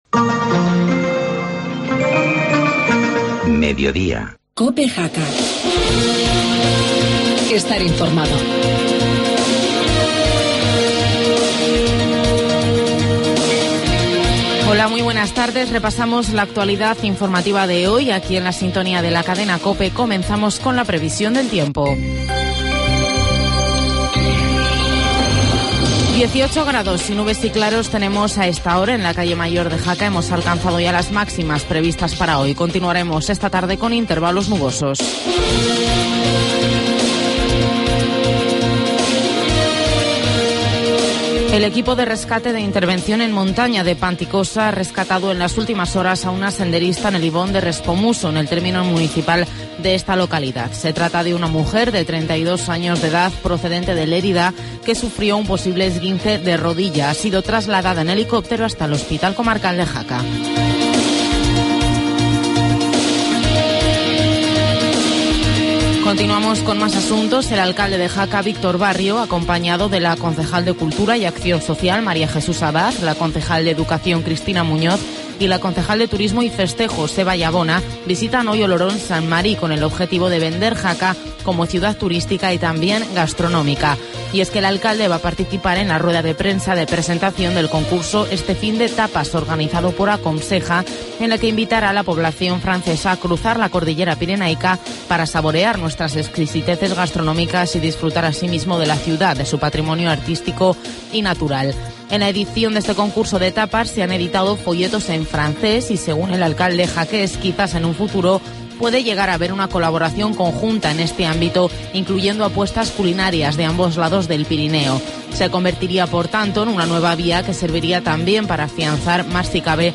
Informativo mediodía, lunes 14 de octubre